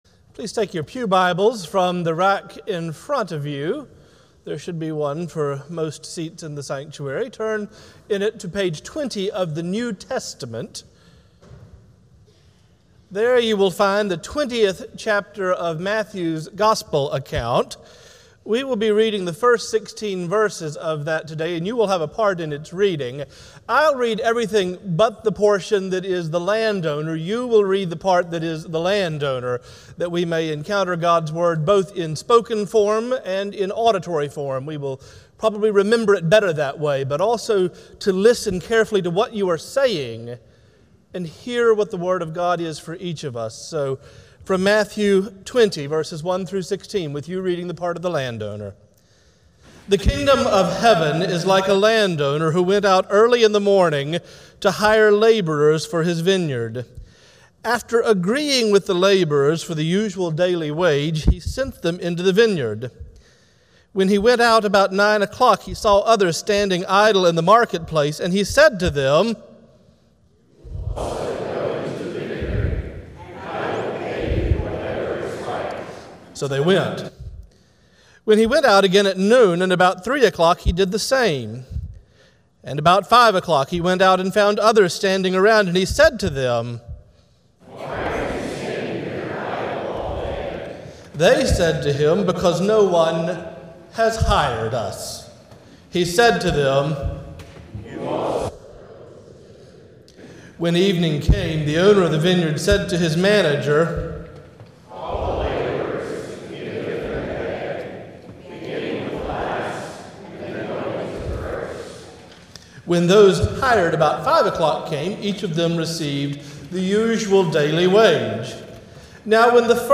Morningside Presbyterian Church - Atlanta, GA: Sermons: Fairness vs. Justice